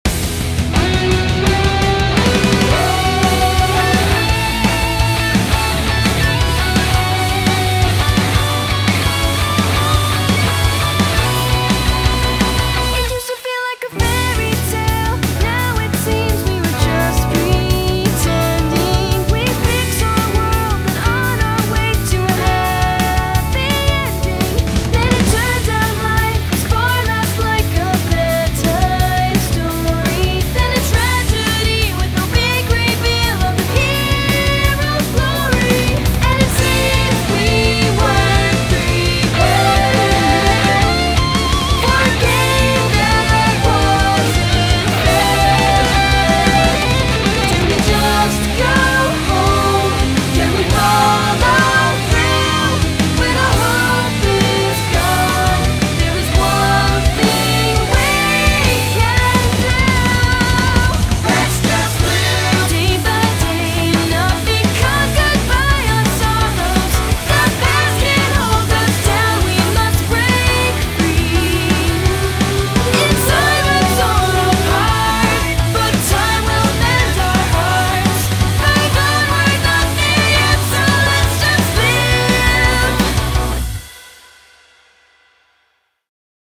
BPM170
Audio QualityPerfect (High Quality)
Opening Theme from Volume 4